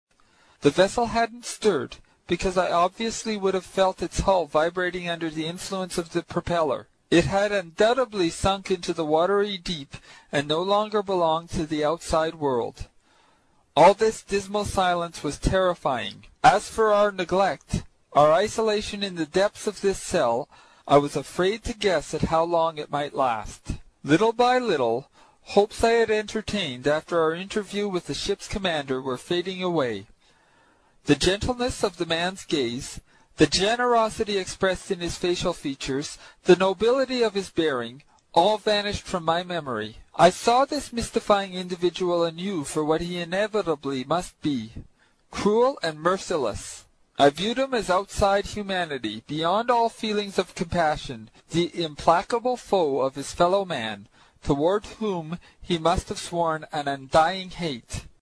英语听书《海底两万里》第128期 第9章 尼德兰的愤怒(13) 听力文件下载—在线英语听力室
在线英语听力室英语听书《海底两万里》第128期 第9章 尼德兰的愤怒(13)的听力文件下载,《海底两万里》中英双语有声读物附MP3下载